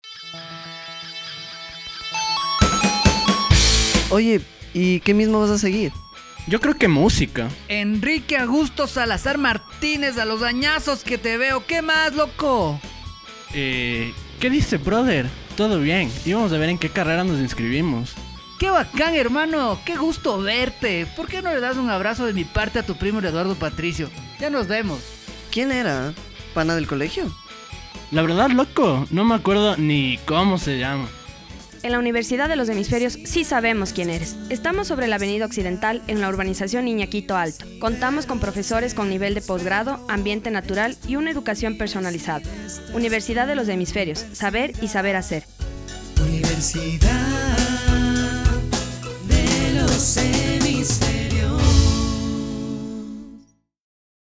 cuña 1.wav(13.69 MB)